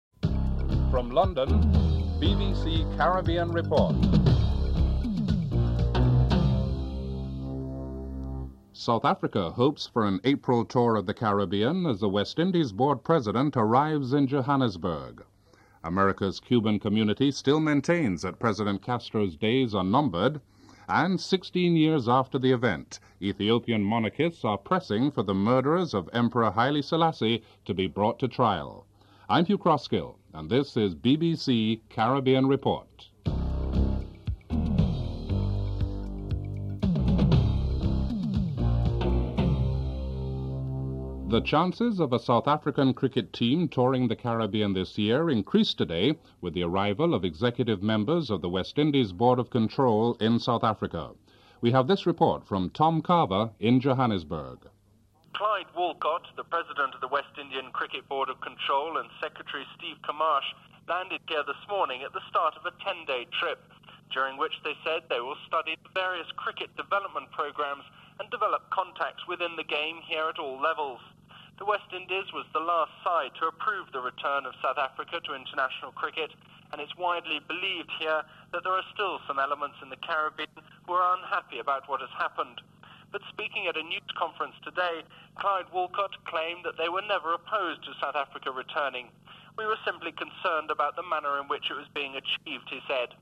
1. Headlines (00:00-00:39)
6. Report on Suriname's government plans for greater control of the army (06:41-07:53)